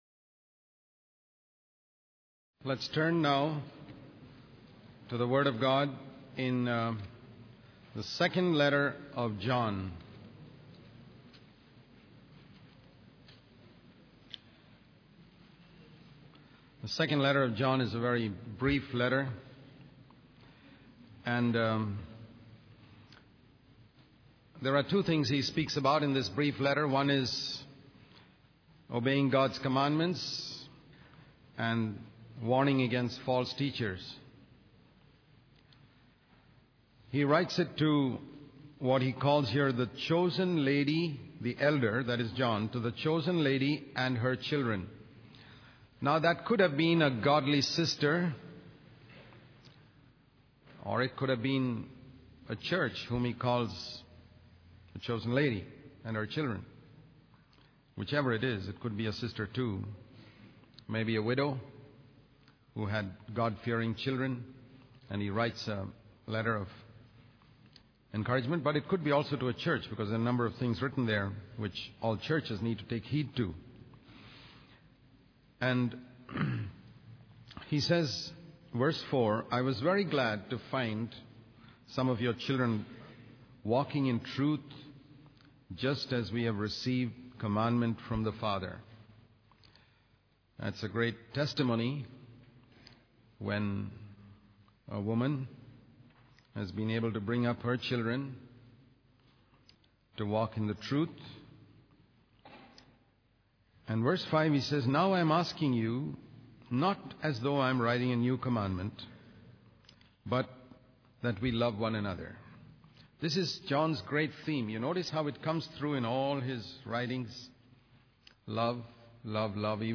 In this sermon, the preacher emphasizes the importance of discernment in understanding the truth.